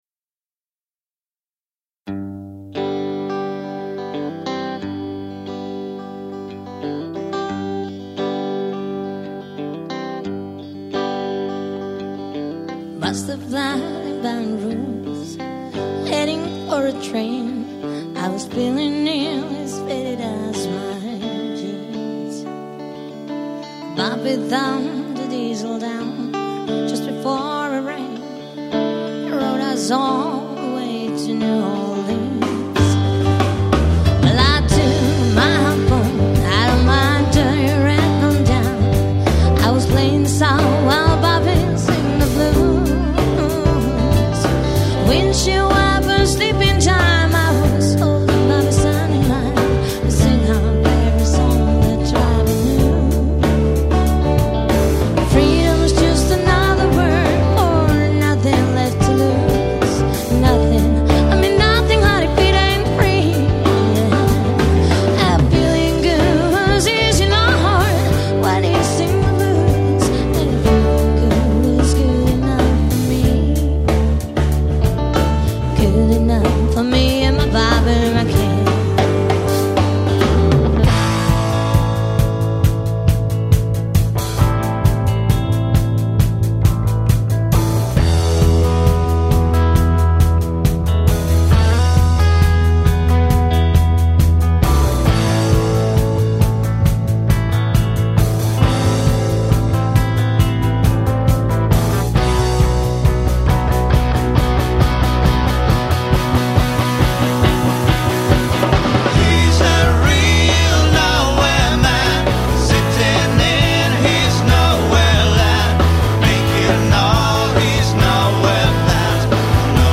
laulu, taustalaulu
kitara (stereokuvassa vasemmalla), laulu, taustalaulu
bassolaulu, taustalaulu
rummut
kitara (stereokuvassa oikealla)
koskettimet